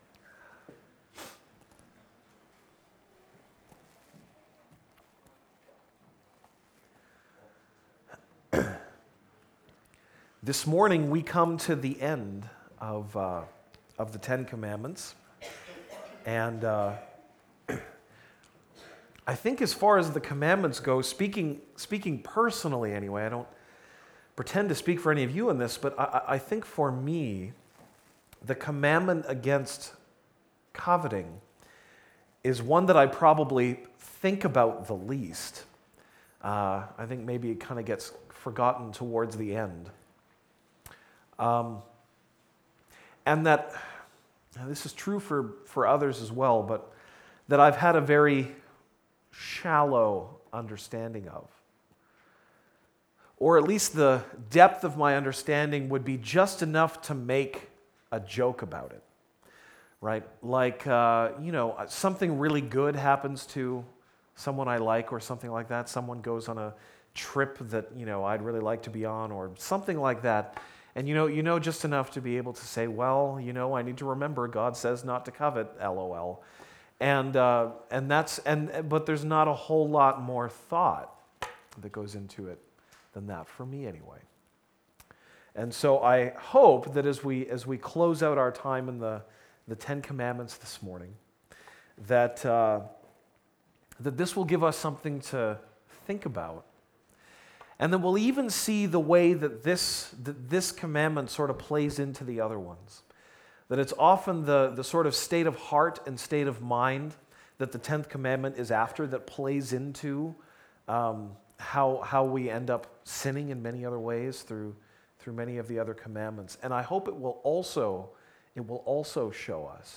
November 12, 2017 (Sunday Morning)